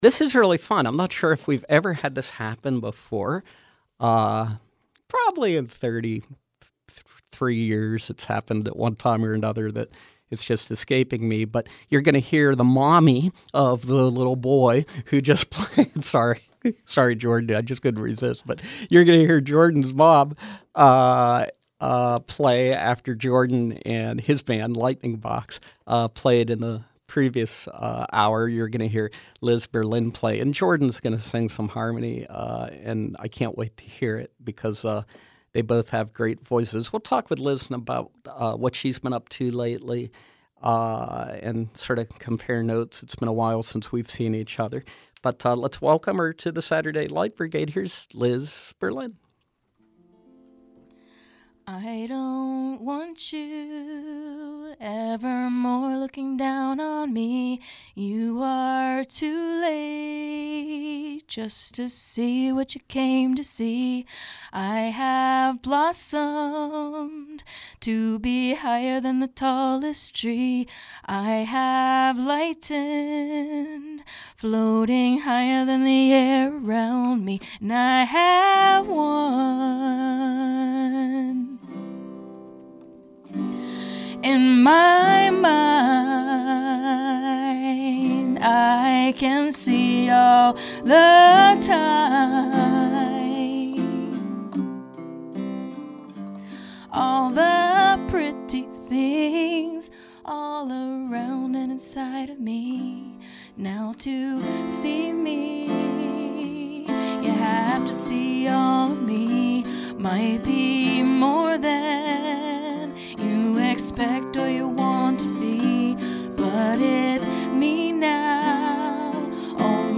acclaimed singer-songwriter
playing soulful acoustic folk and rock